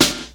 • Old School Hip-Hop Snare Drum B Key 80.wav
Royality free steel snare drum sample tuned to the B note. Loudest frequency: 3587Hz
old-school-hip-hop-snare-drum-b-key-80-S33.wav